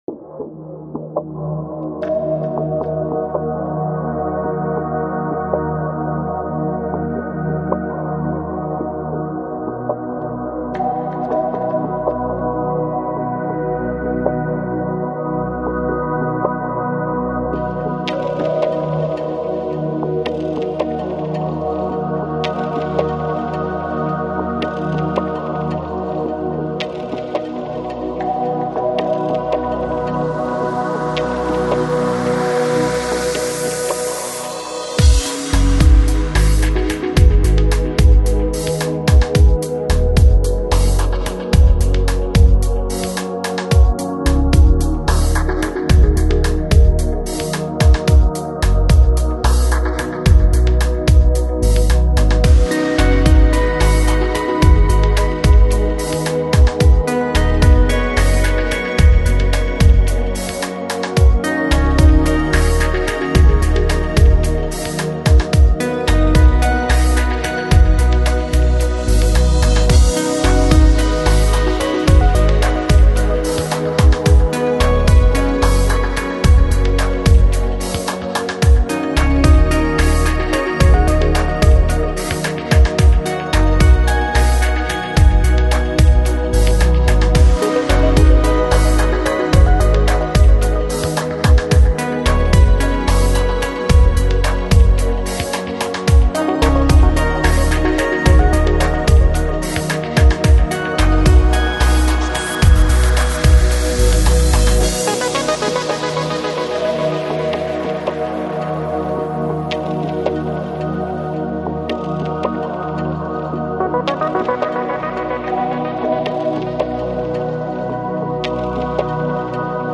Жанр: Electronic, Downtempo, Lounge, Chill Out